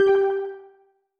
Techmino/media/effect/chiptune/ren_10.ogg at 9864a75e0f51760a53e0ef93eaf0fa4fa8ebbc2d